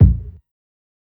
KICK_SOLAR.wav